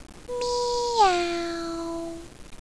Several Ryo-Ohki sounds!
Spaceship Meow
meoooow.wav